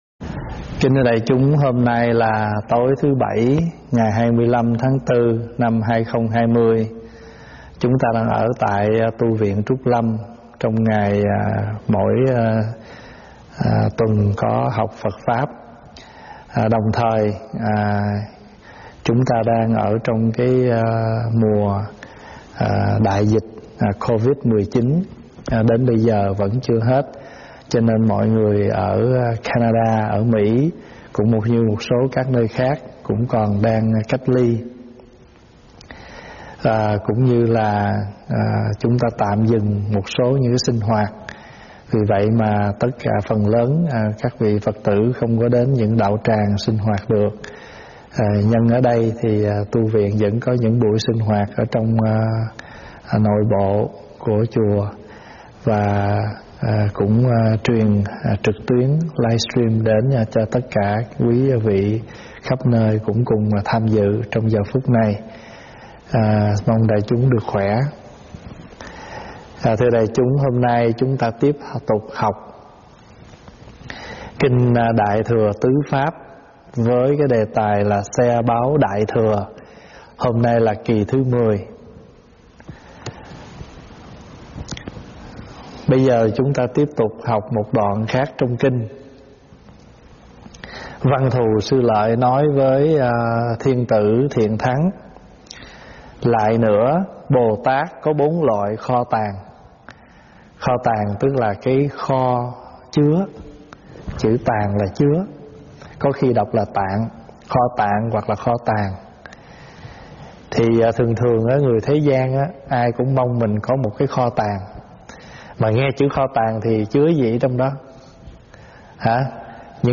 Nghe Mp3 thuyết pháp Kho Tàng Của Người Tu Có Những Gì?
giảng livestream tại tv Trúc Lâm